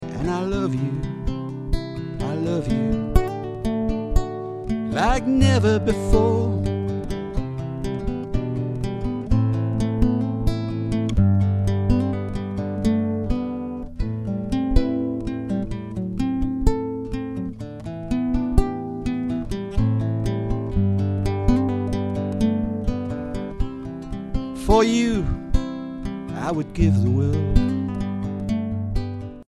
Ashington Folk Club - Spotlight 20 October 2005
classical guitar